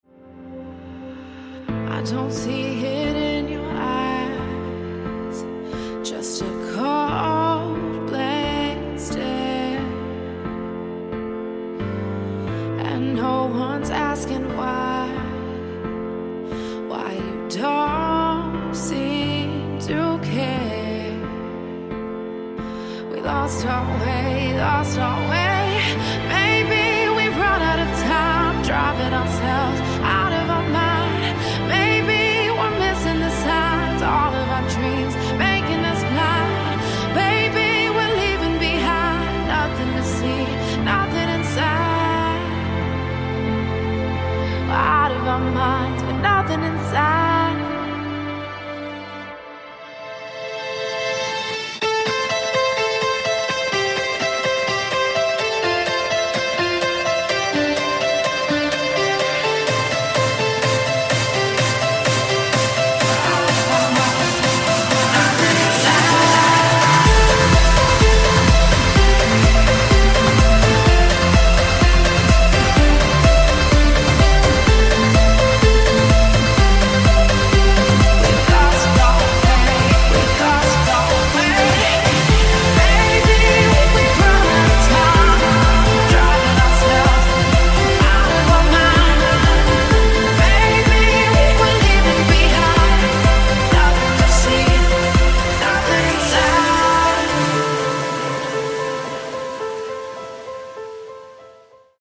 • Качество: 256, Stereo
женский вокал
dance